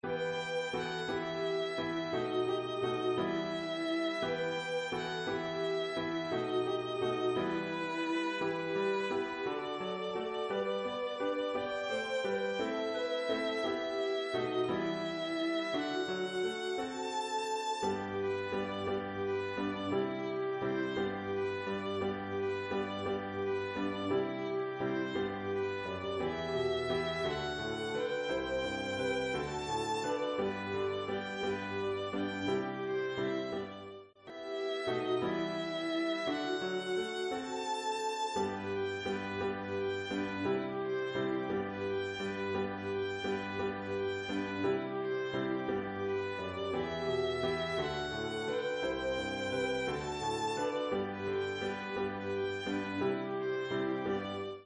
Music sample = Descant #1, then end of Descant #2.
Instrumental Violin